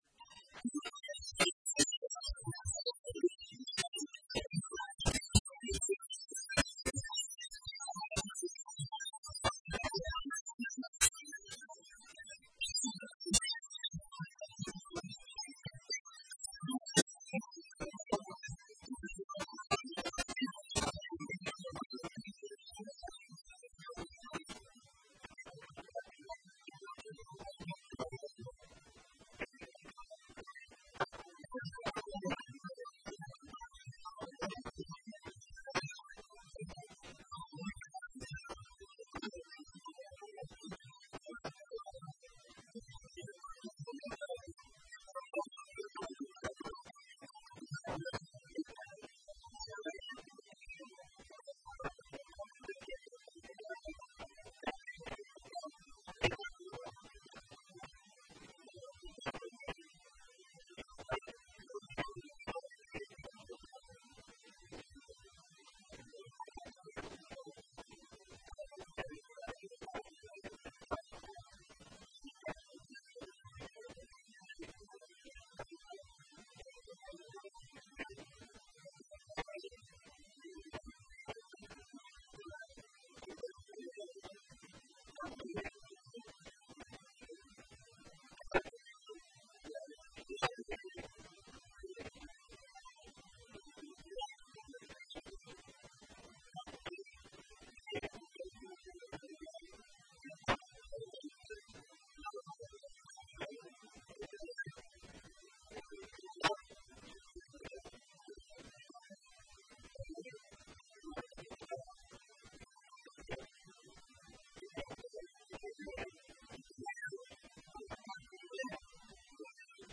Conferencia de Prensa del Intendente Municipal Dr. Eduardo "Bali" Bucca